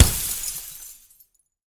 breakglass.ogg.mp3